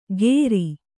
♪ gēri